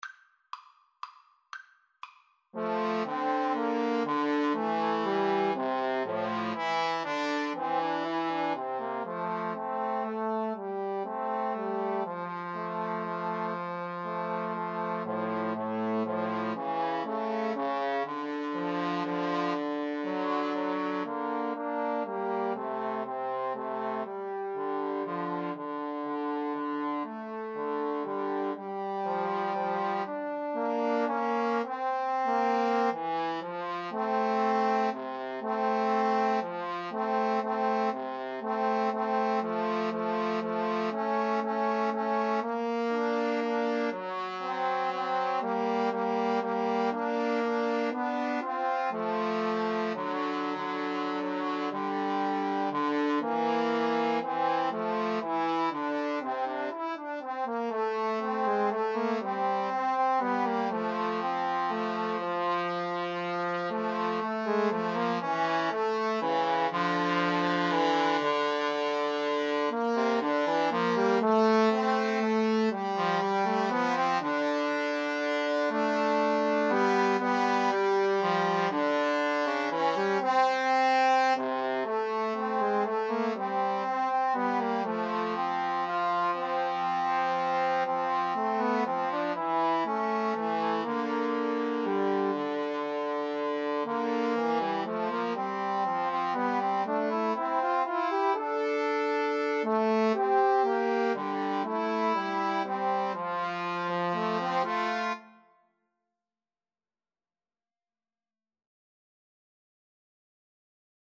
Trombone 1Trombone 2Trombone 3
= 120 Tempo di Valse = c. 120
3/4 (View more 3/4 Music)